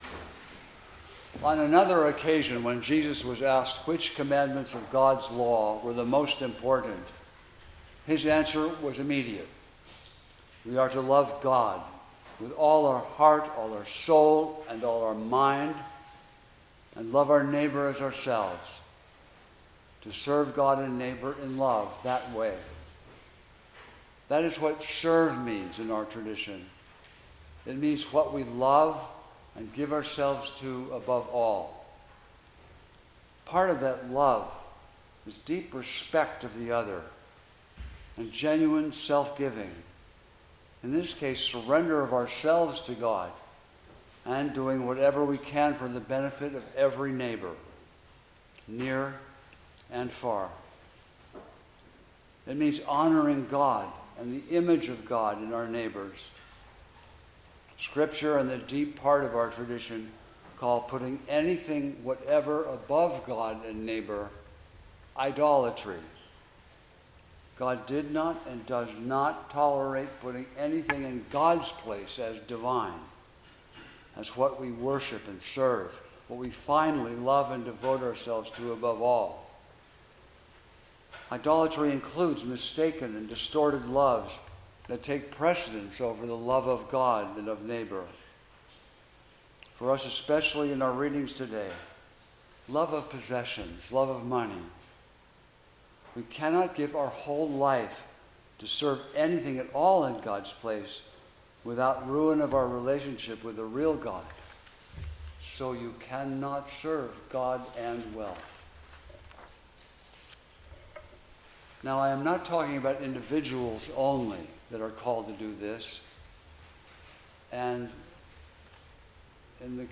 Preacher
Service Type: 10:00 am Service